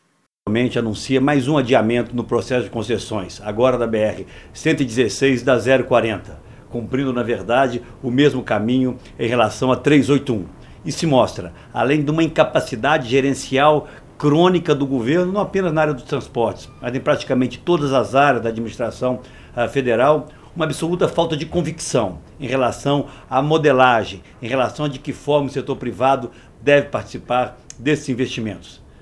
Fala do senador Aécio Neves